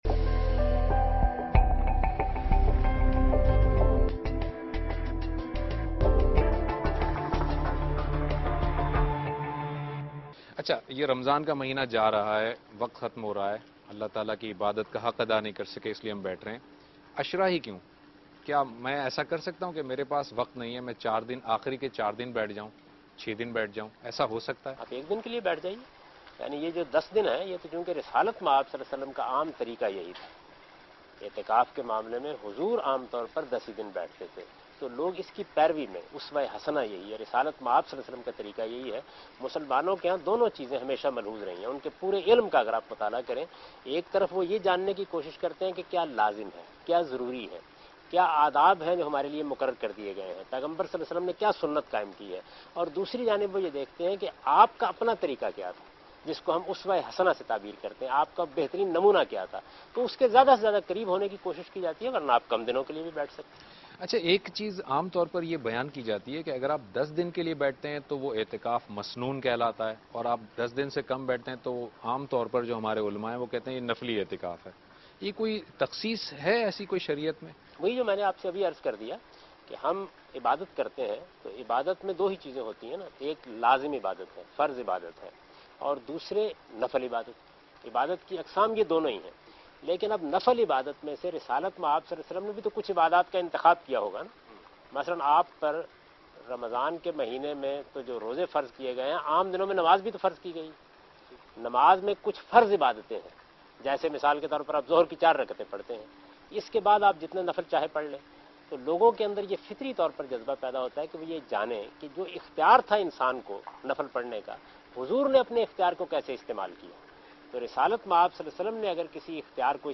Category: TV Programs / Dunya News / Deen-o-Daanish / Questions_Answers /